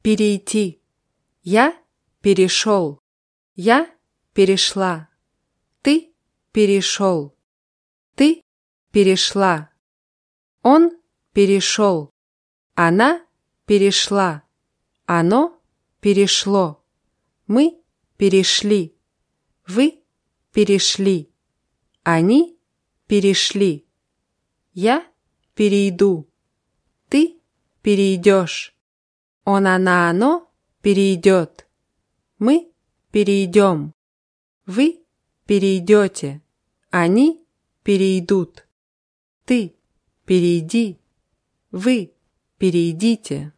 перейти [pʲirʲijtʲí]